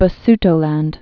(bə-stō-lănd)